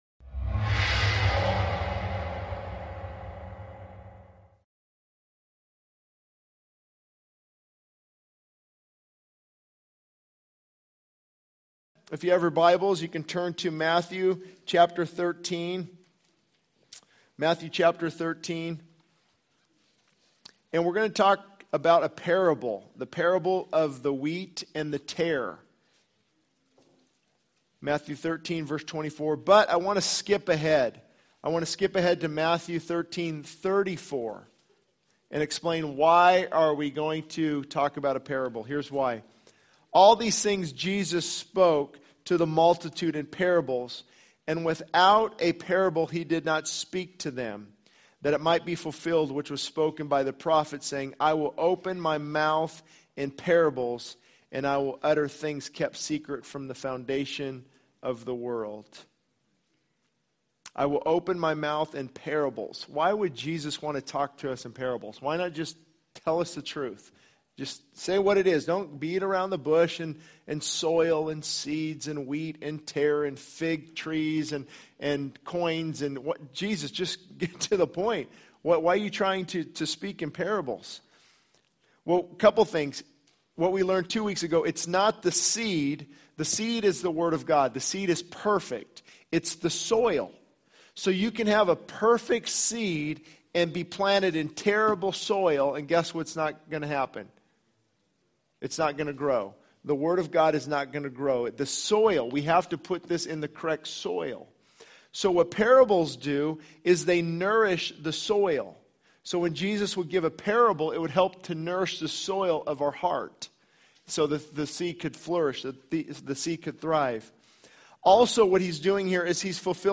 This sermon delves into the importance of self-examination and the use of parables by Jesus to awaken hearts. It emphasizes the need for worship, prayer, and repentance to transform lives and warns about the consequences of spiritual slumber and the judgment to come.